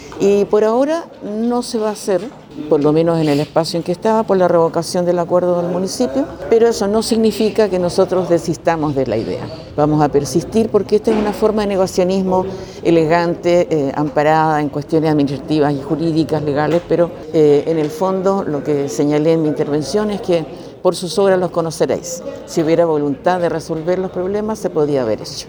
Para su par del Partido Comunista, Olimpia Riveros, los problemas para el Museo de la Memoria partieron con el traspaso de la Gobernación desde Rodrigo Díaz a Sergio Giacaman sin el financiamiento para esta iniciativa.